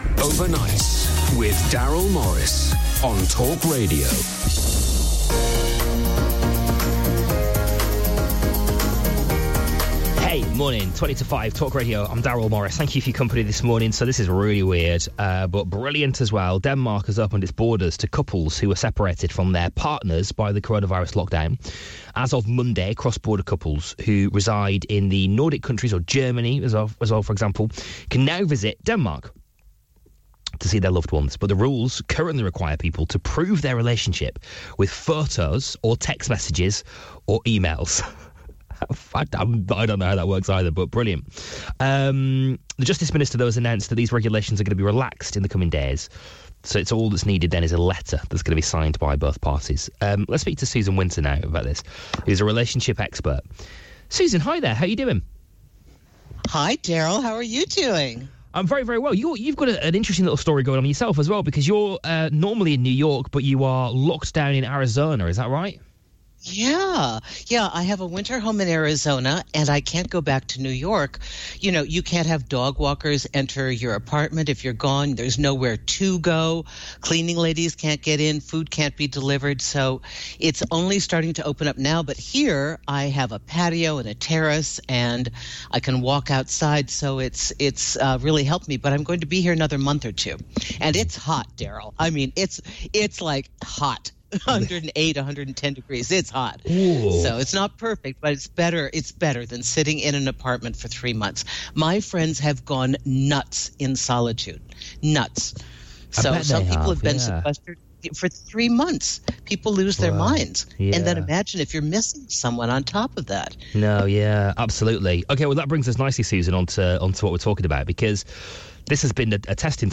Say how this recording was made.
My radio interview with talkRadio London discusses Denmark opening its borders to partners separated during the quarantine.